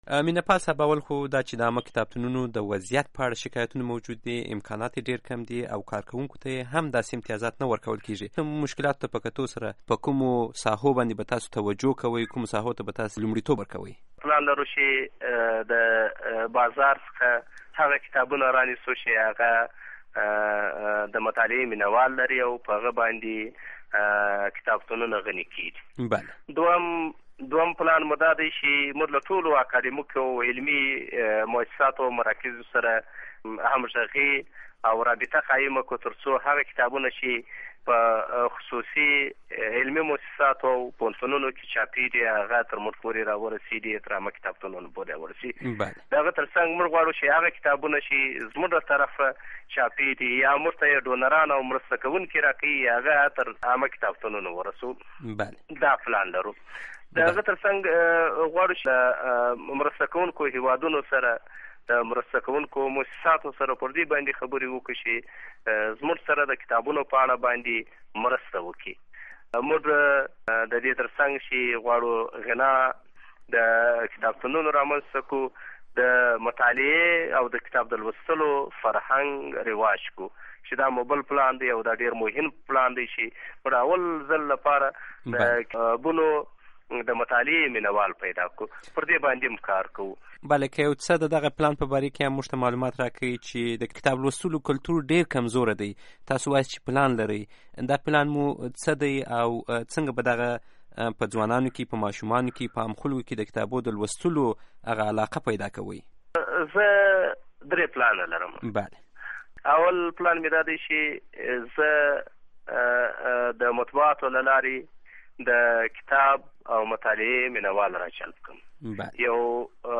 Interview with Meenapal